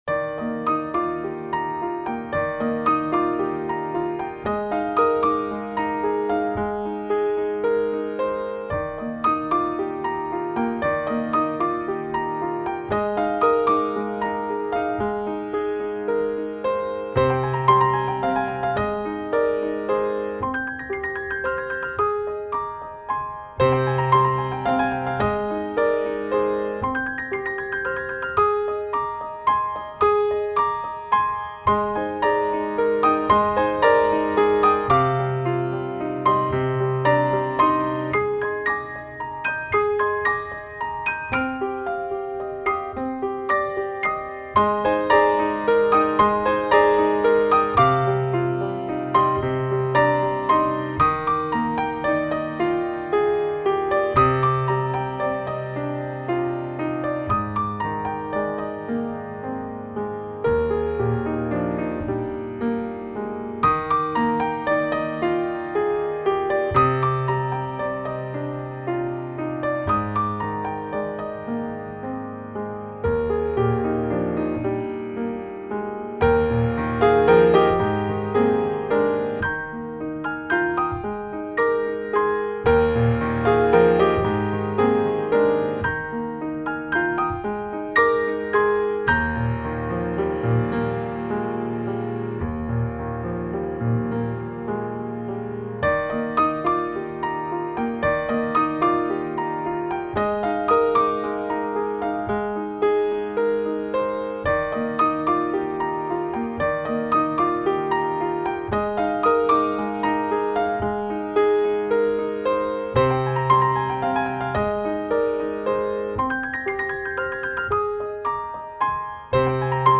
[light]沐浴都會日落時分的煦和暖意 輕拂喧囂紛擾後的心靈澄淨[/light]